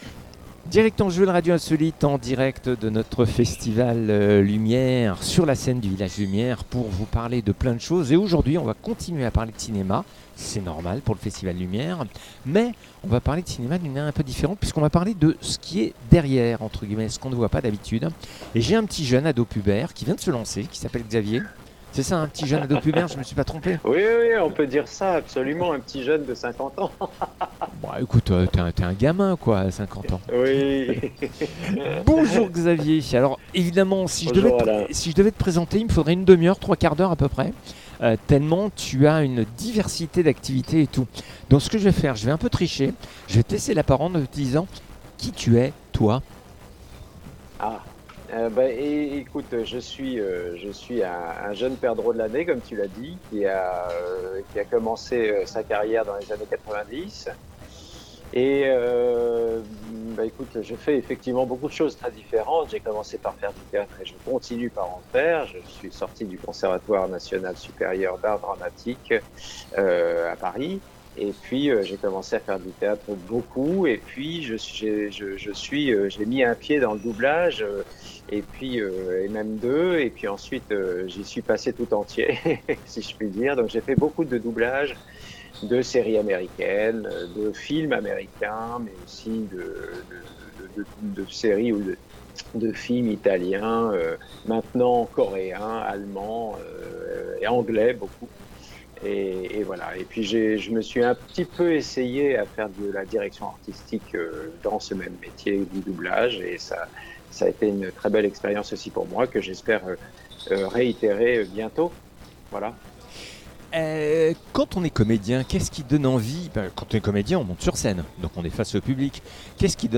comédien et voix de doublage en direct du Festival Lumière